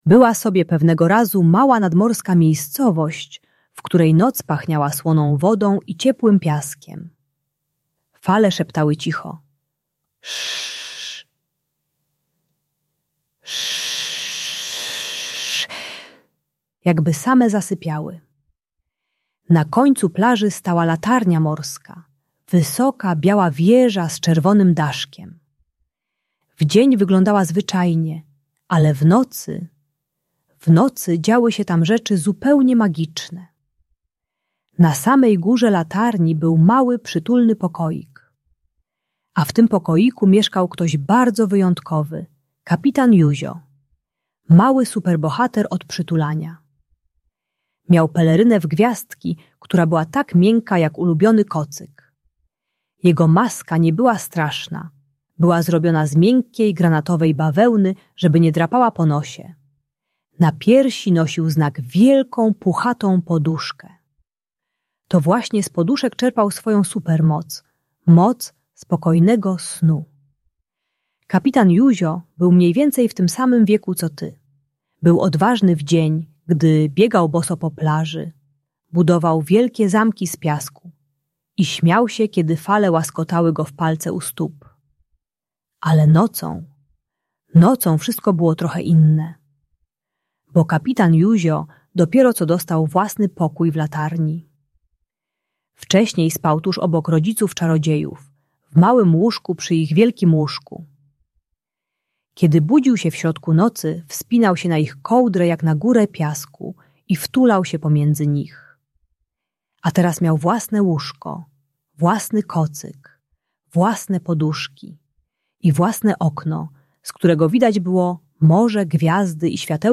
Magiczna historia o Kapitanie Józio i latarni - Usypianie | Audiobajka
Ta bajka dla dziecka które boi się spać samo uczy techniki "Latarni Dobrej Nocy" - rytuału uspokajającego z wizualizacją bezpieczeństwa. Idealna audiobajka usypiająca dla dzieci 3-5 lat przechodzących na własny pokój.